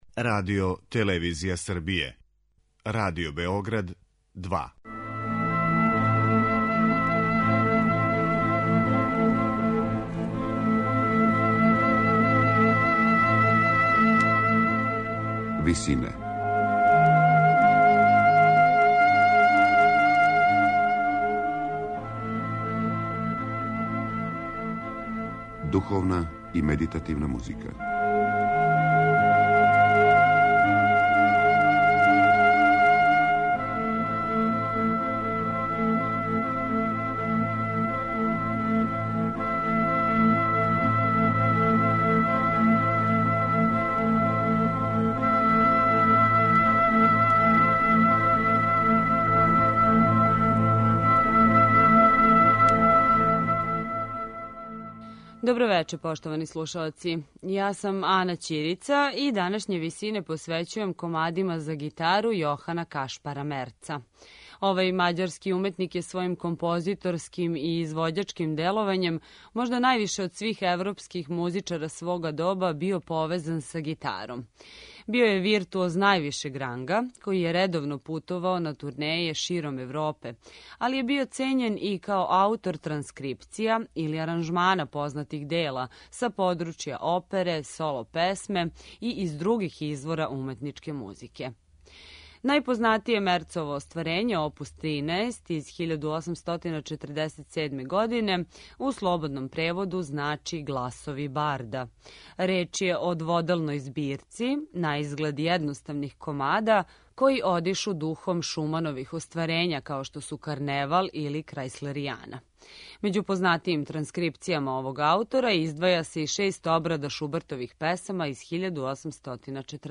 Данашњу емисију посвећујемо комадима за гитару мађарског уметника Јохана Кашпара Мерца.